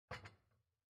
烹饪 " 放盘子1
标签： 厨房 桌子 推杆 午餐
声道立体声